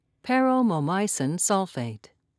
(par-oh-moe-mye'sin)